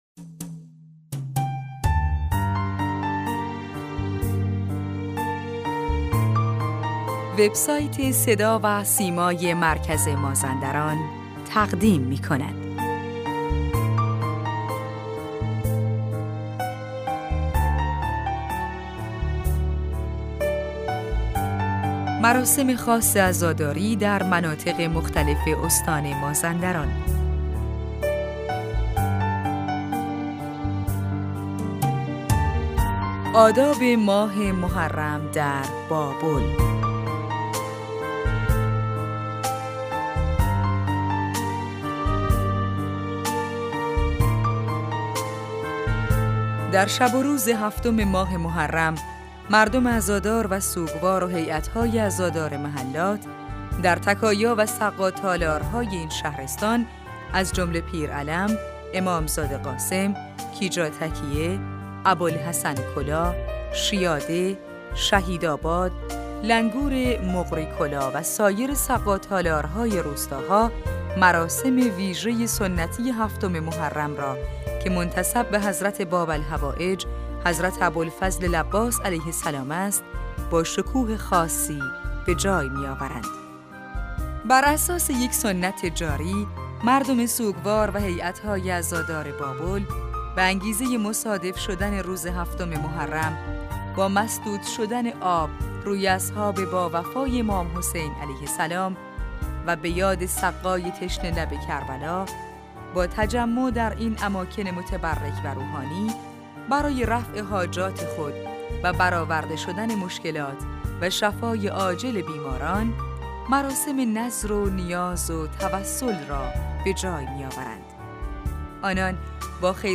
چند رسانه: مراسم خاص عزاداری در مناطق مختلف استان مازندران برگزار می‌شود و این مراسم در شهرستان بابل از حال و هوای خاصی برخوردار است.